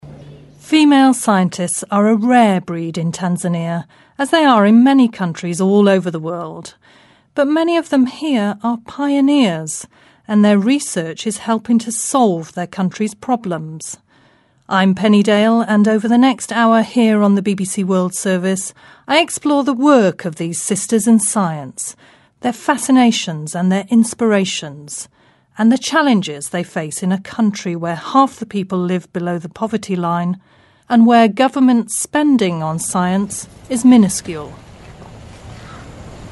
【英音模仿秀】坦桑尼亚科学巾帼 听力文件下载—在线英语听力室